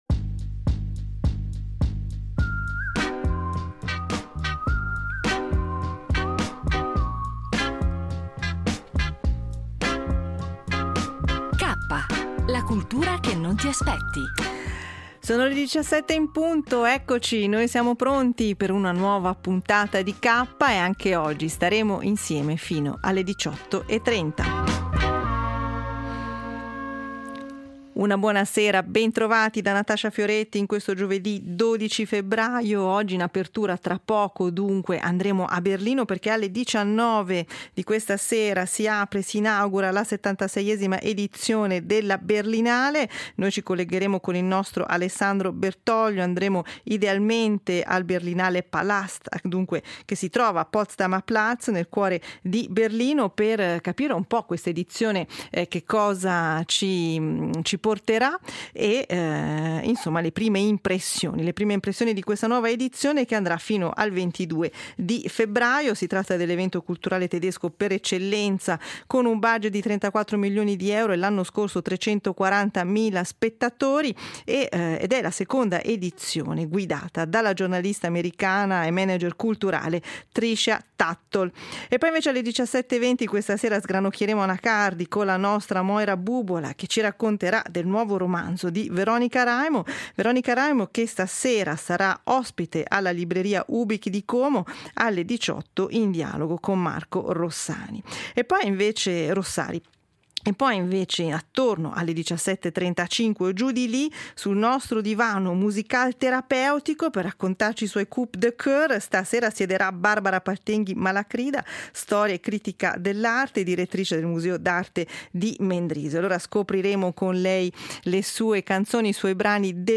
Partiamo oggi con un collegamento in diretta dalla 76esima edizione della Berlinale , esplorando le prime impressioni e le aspettative di questo importante evento cinematografico. A seguire, un’intervista con Veronica Raimo, che ha svelato i retroscena del suo nuovo romanzo “Non scrivere di me” , soffermandosi sulla sfida di ritrovare l’ispirazione dopo il successo e sulla complessità della protagonista.